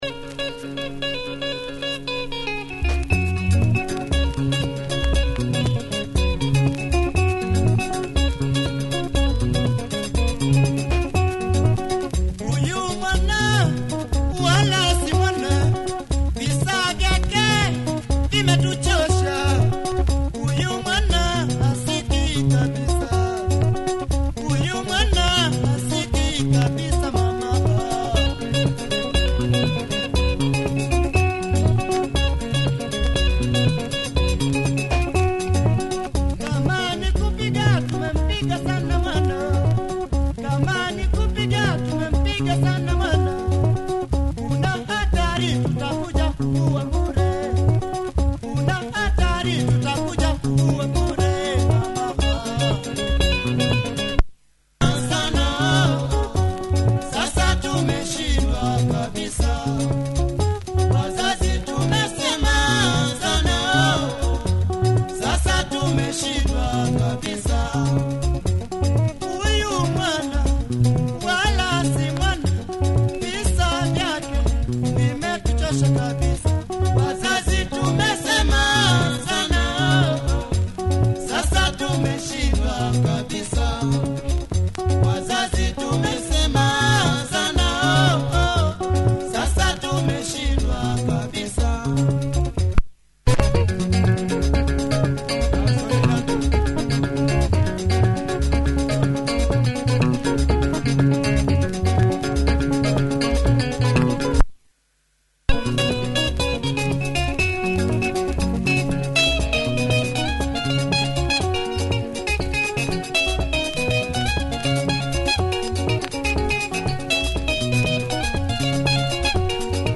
nice drive and breakdown